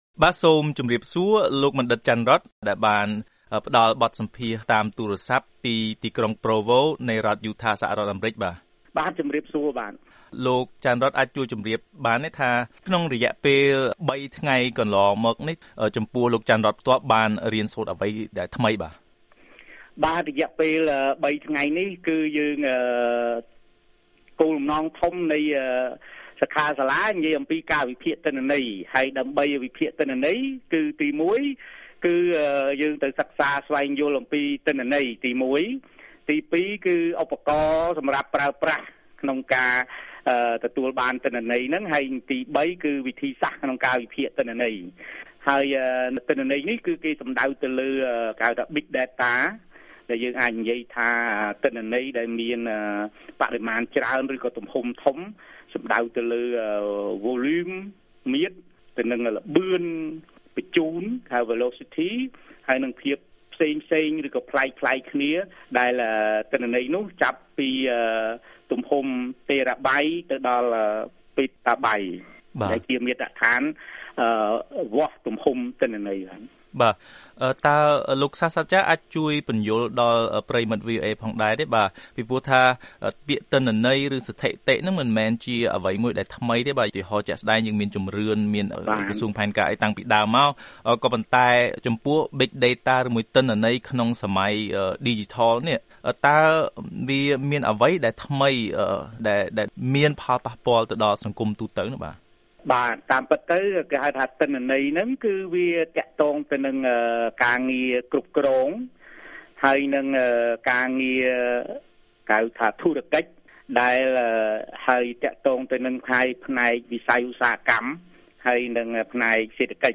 បទសម្ភាសន៍ VOA ៖ ការចេះវិភាគទិន្នន័យទំហំធំ Big Data មានសារៈសំខាន់សម្រាប់គ្រប់វិស័យរបស់កម្ពុជា